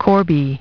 Транскрипция и произношение слова "corby" в британском и американском вариантах.